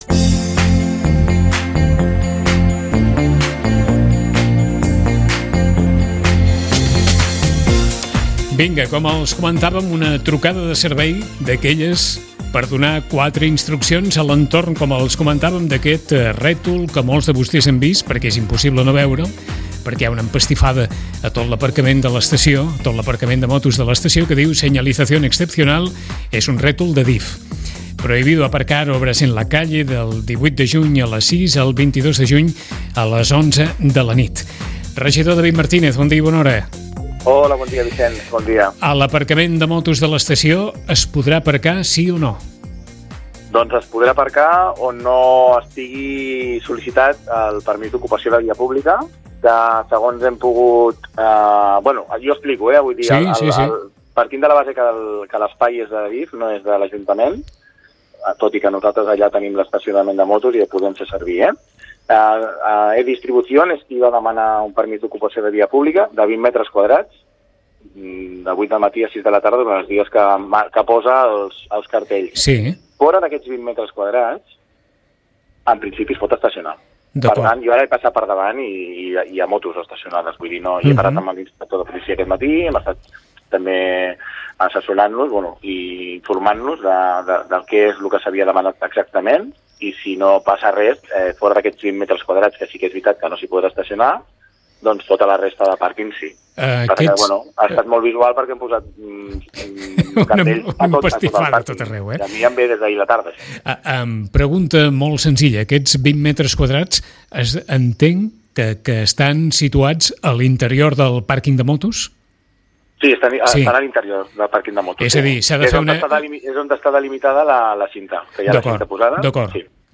Fora d’aquest espai es pot aparcar sense problema. Ens ho confirma el regidor de mobilitat David Martinez.